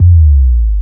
BASS61  02-R.wav